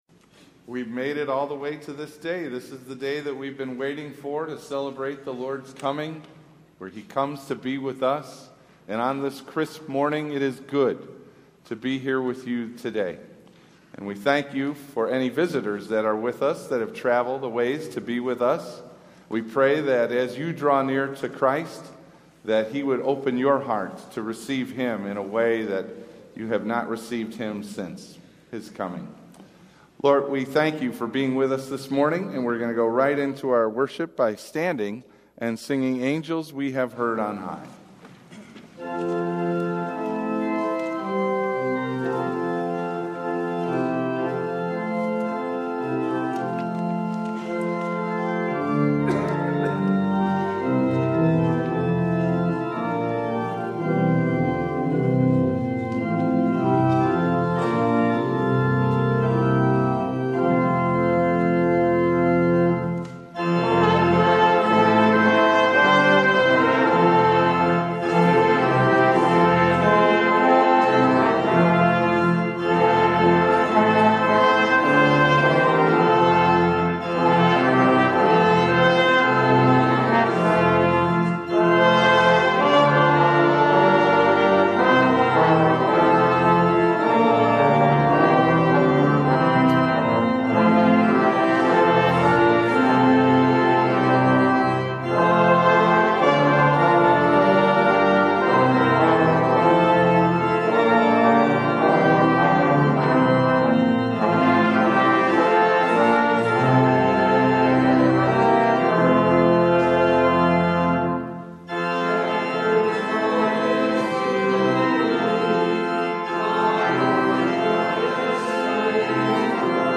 Dec 25 / 10:00am Christmas Day – Do You See What I See? – Lutheran Worship audio